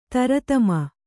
♪ taratama